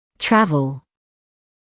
[‘trævl][‘трэвл]перемещаться, двигаться